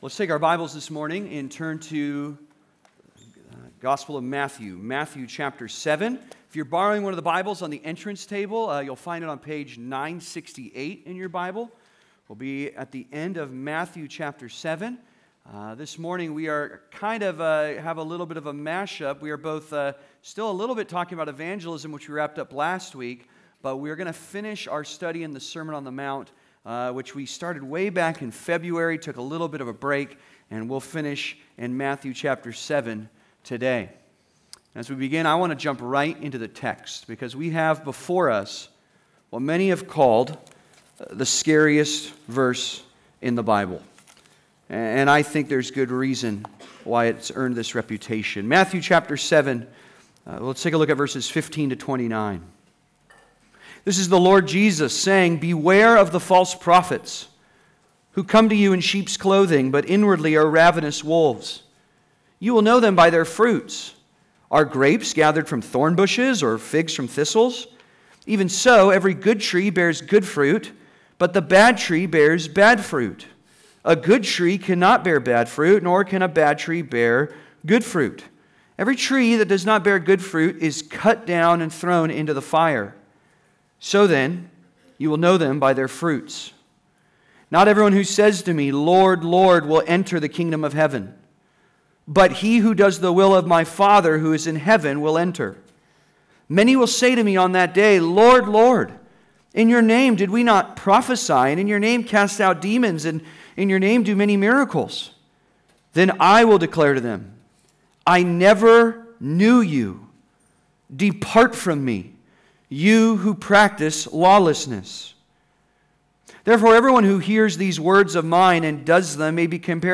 Evangelism and False Converts (Sermon) - Compass Bible Church Long Beach